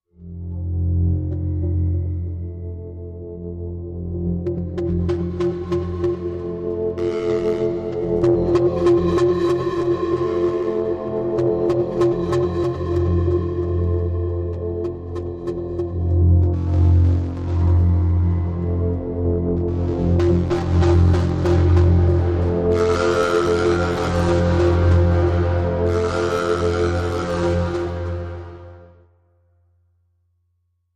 Pulse Rattle Low Generator Pulse Steady